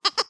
bobbycar_horn-007.wav